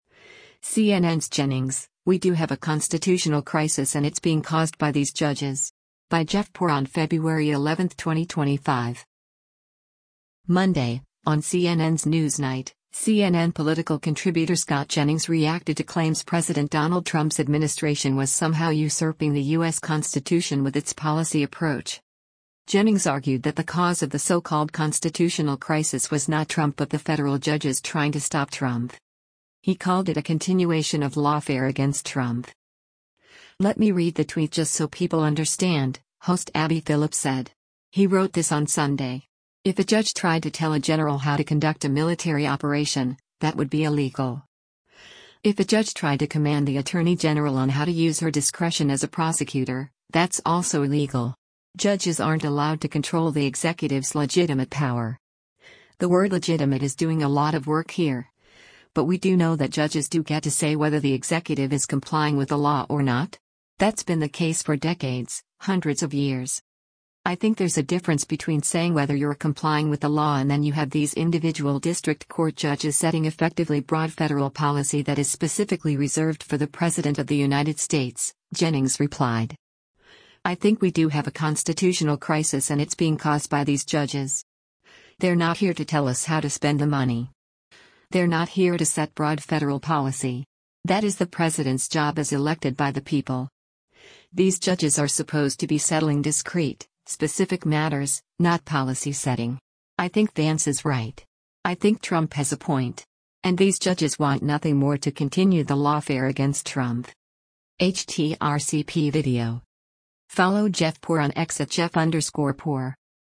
Monday, on CNN’s “NewsNight,” CNN political contributor Scott Jennings reacted to claims President Donald Trump’s administration was somehow usurping the U.S. Constitution with its policy approach.